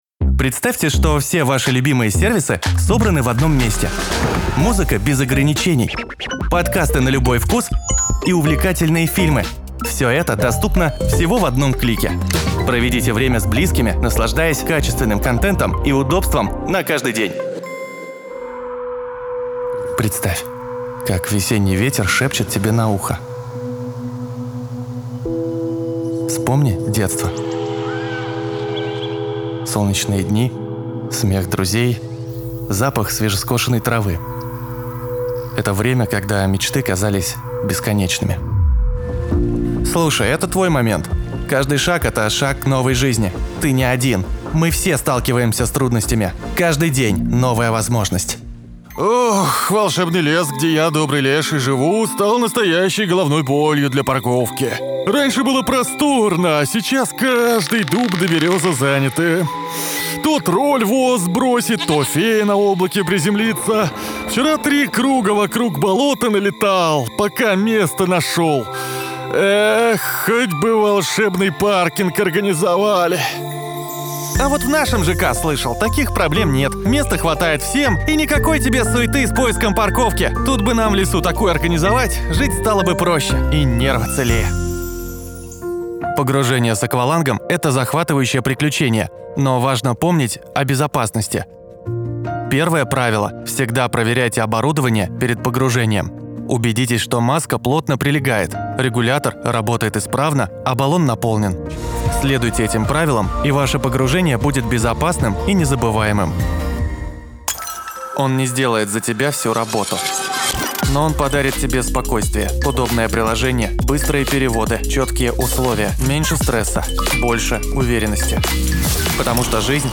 Пример звучания голоса
Муж, Рекламный ролик/Молодой
Условия записи: Полностью оборудованная и изолированная студийная кабина, микрофон Audio-Technica АТ4040 + аудио интерфейс PreSonus Studio 192.